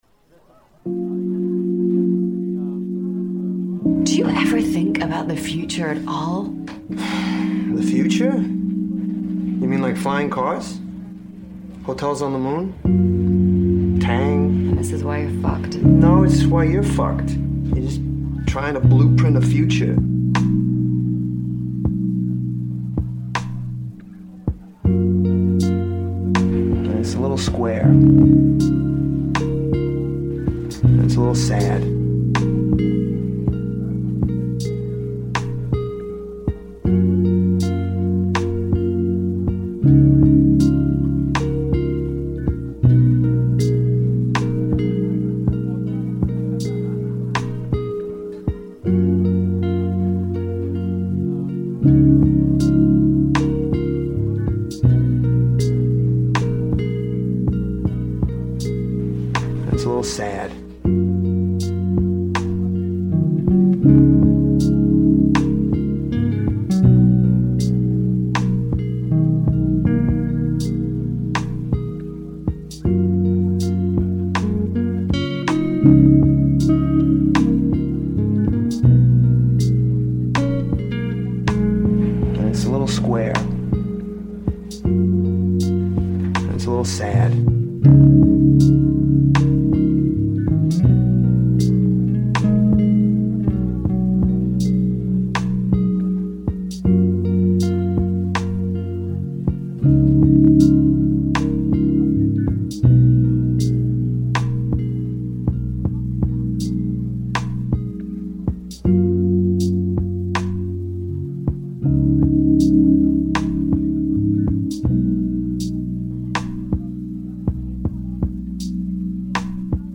Session 1h : Ambiance Café Réelle